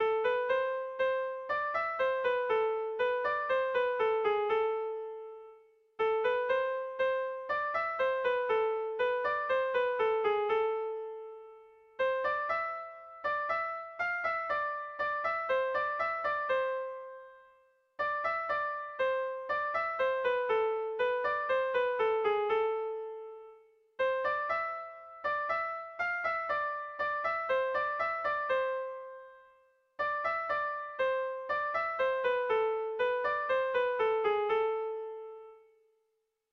Erlijiozkoa
AAB1B2